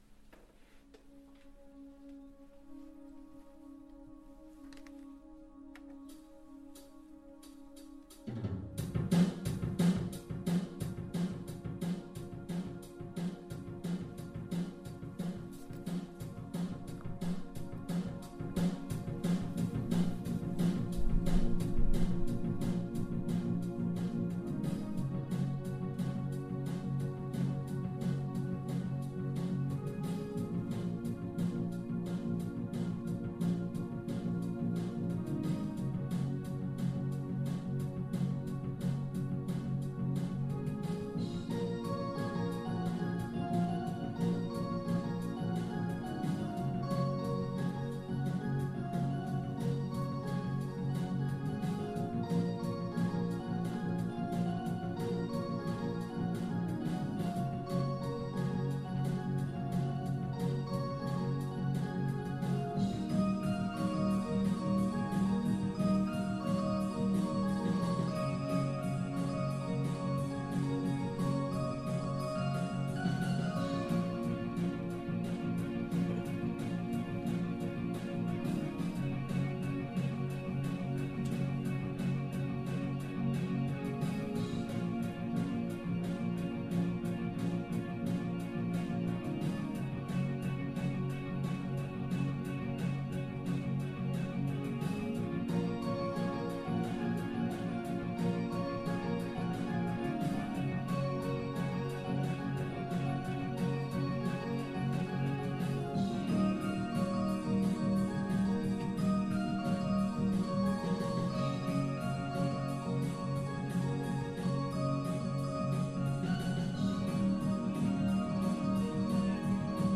Piano Recital Oct 17